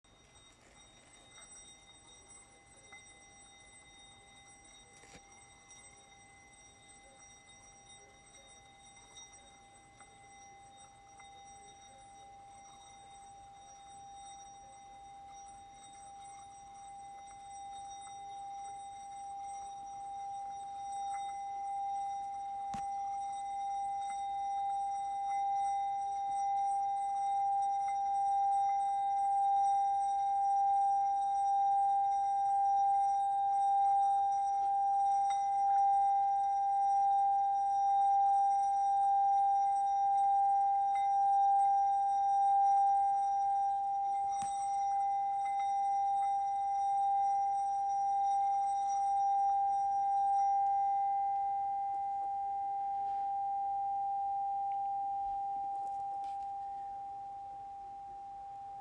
Ecoutez les vibrations du bol en coffret avec son maillet de 16 mm de diamètre:
Les vibrations régulières se produisent rapidement.
vibrations-coffret.m4a